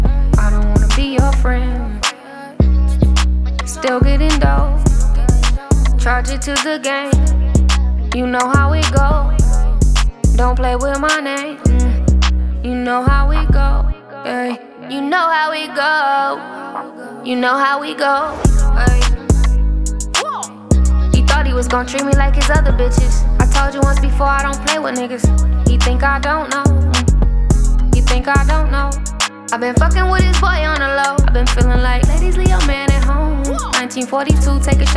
Жанр: Иностранный рэп и хип-хоп / R&b / Соул / Рэп и хип-хоп